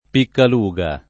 [ pikkal 2g a ]